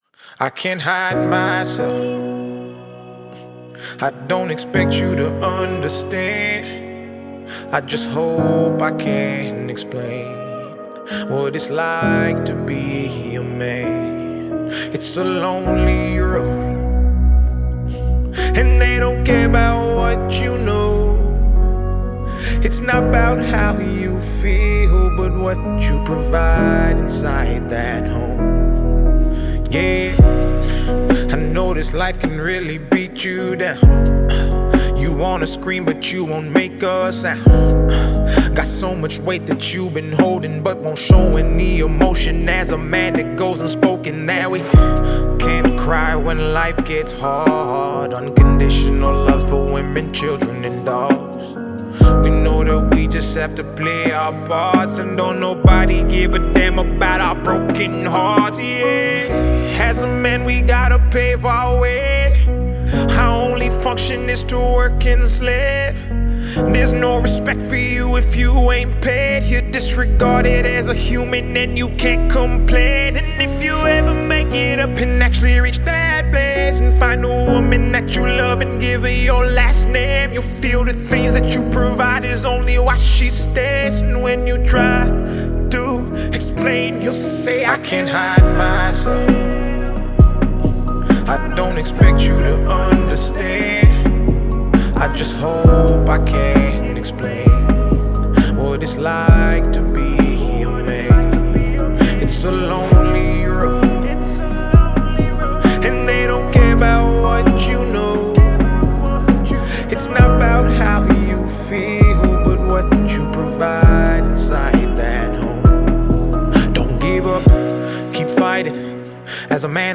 a very skilled Nigerian -Canadian rap genius